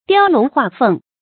雕龍畫鳳 注音： ㄉㄧㄠ ㄌㄨㄙˊ ㄏㄨㄚˋ ㄈㄥˋ 讀音讀法： 意思解釋： 刻繪龍鳳 出處典故： 胡世香《巧取陳家寨》：「門上 雕龍畫鳳 ，粉壁襯著紅漆家具，分外耀眼。」